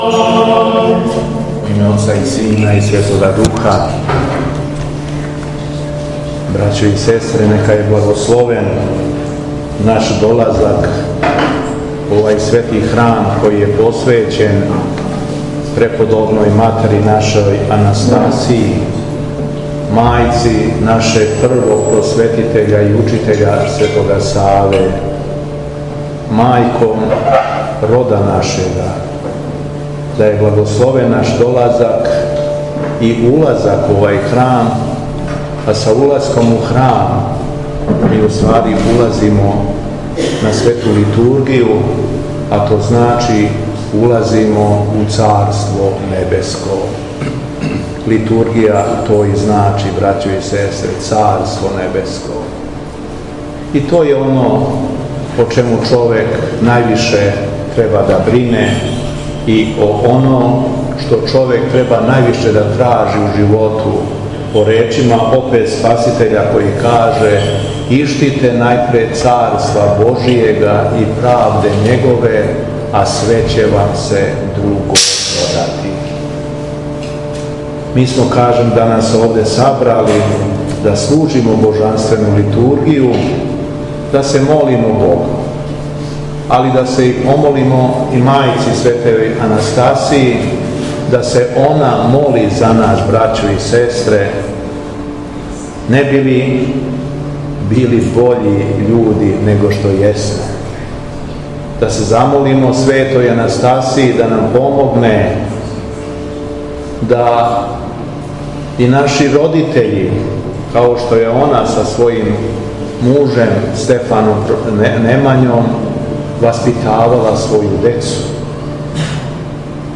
ХРАМОВНА СЛАВА СВЕТА АНАСТАСИЈА СРПСКА У КОПЉАРИМА
Беседа Његовог Преосвештенства Епископа шумадијског г. Јована
Његово Преосветенство, Епископ шумадијски Господин Јован је дана 5. јула 2022. године Господње свештенослижио у Копљарима код Аранђеловца у храму посвећеном Светој Анастасији Српској.